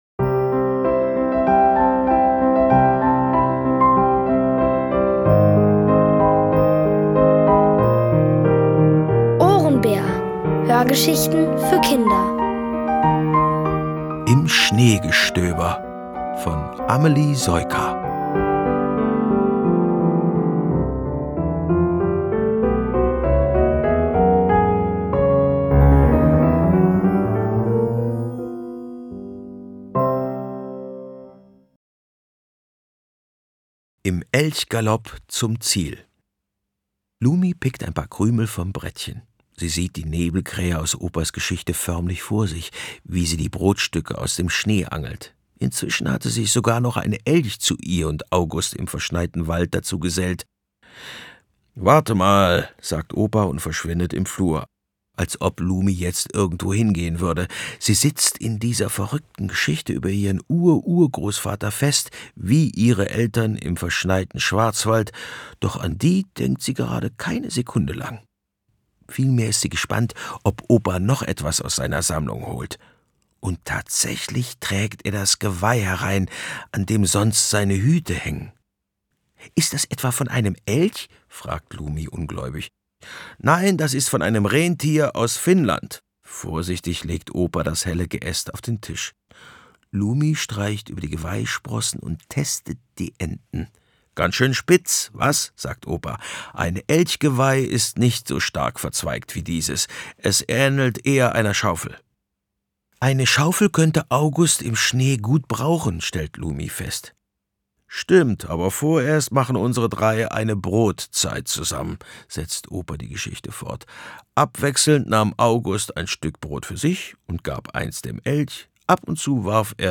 Von Autoren extra für die Reihe geschrieben und von bekannten Schauspielern gelesen.
Es liest: Bernhard Schütz.